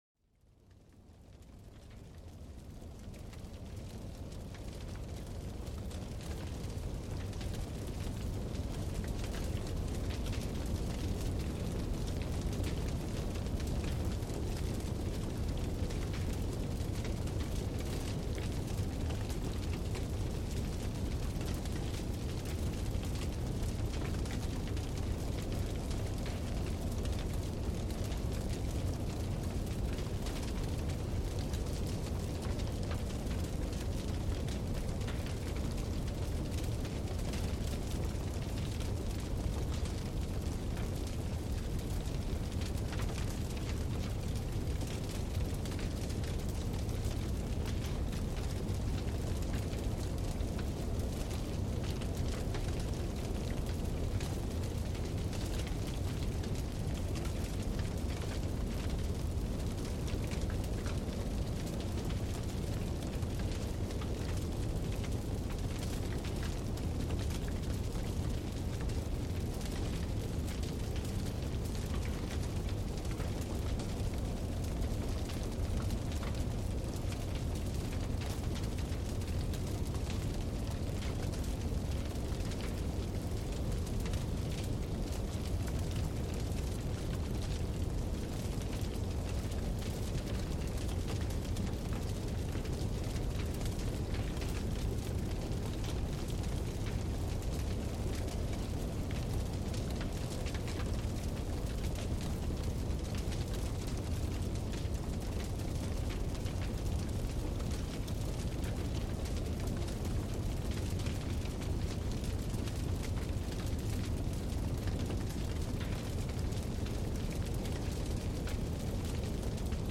Découvrez les crépitements réconfortants du feu dans cet épisode unique, où chaque étincelle raconte une histoire. Laissez-vous envelopper par la chaleur enveloppante et les sons apaisants du bois qui se consume, un véritable hymne à la relaxation.